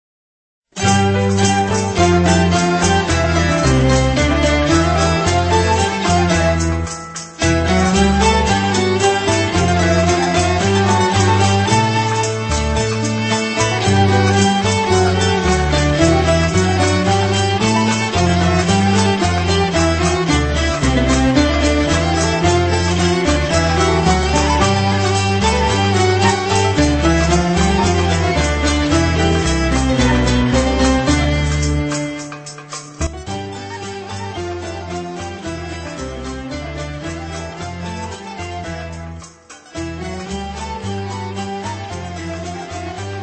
Un aperçu du début de la Touchia Raml_Al_Maya à Alger et sa structure (supposée) en douze temps.
Version du début - Option 12 temps -